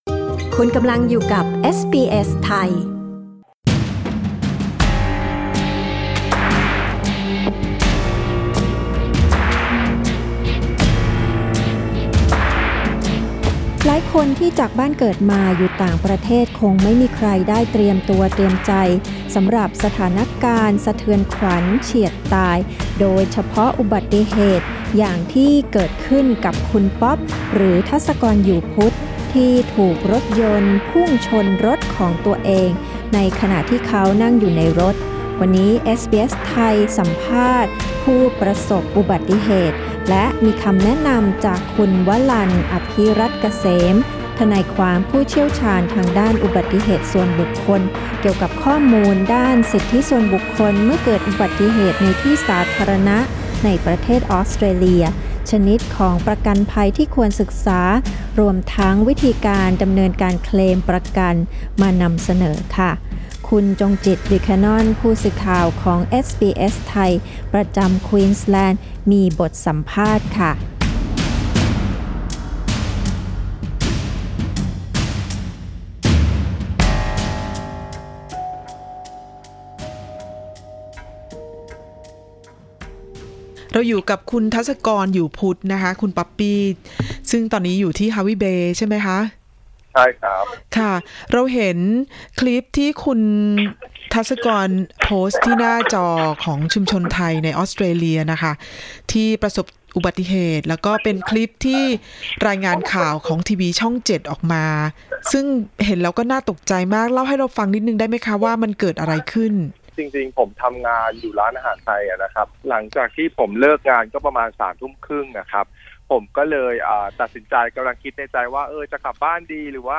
สัมภาษณ์นี้เผยแพร่ครั้งแรกเมื่อ 16 กรกฎาคม 2564